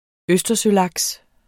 Udtale [ ˈøsdʌsøˌlɑgs ]